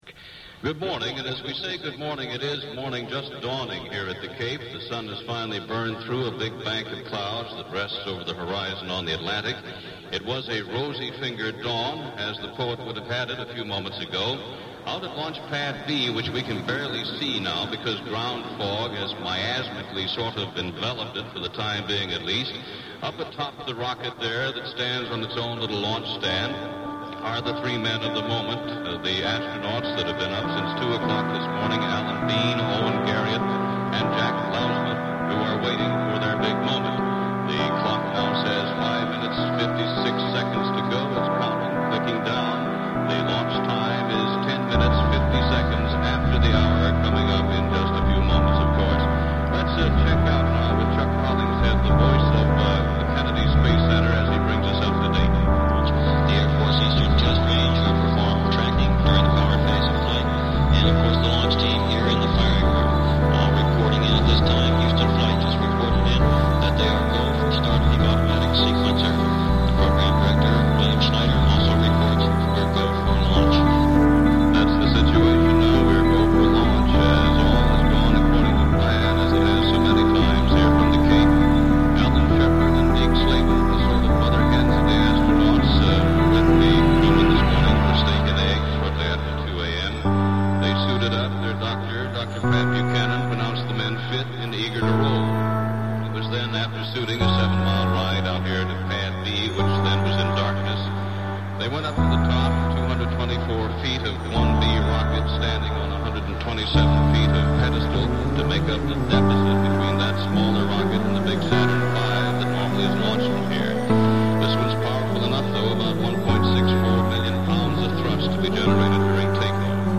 Guitar
You've made this album delightfully bizarre.